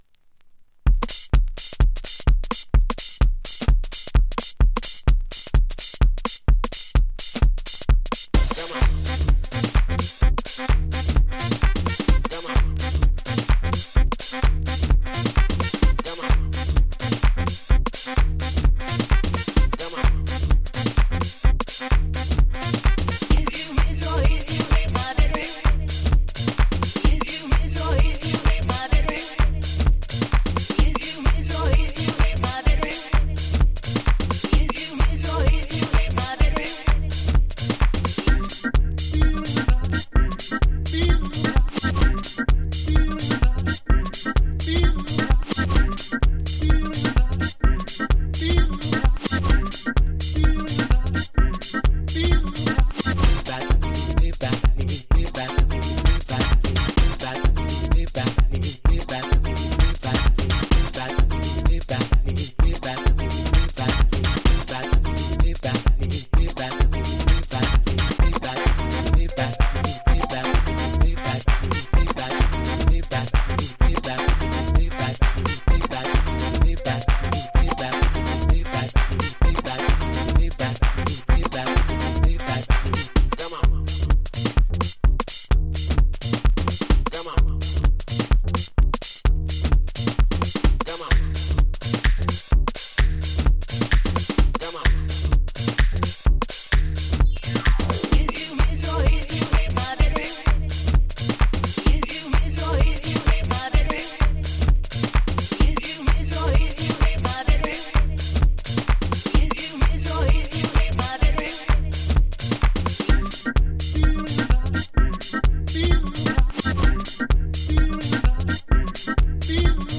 この揺らぎって機材のものなのかreel-to-reel由来のものなのかわかりませんが、結果として良い味になってます！